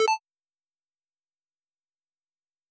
pause.wav